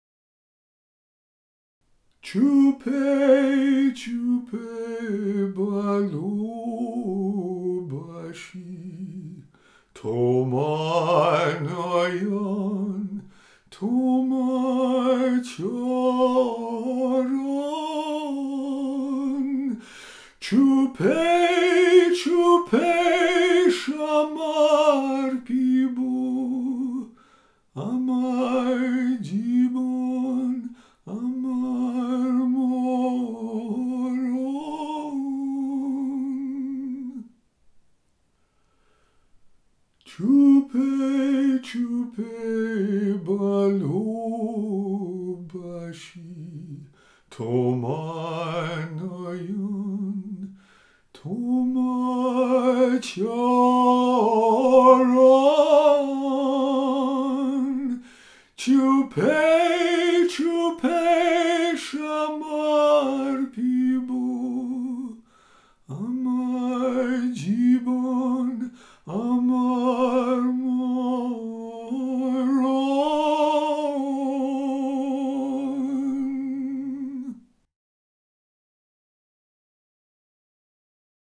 Music for meditation and relaxation.